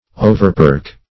Overperch \O`ver*perch"\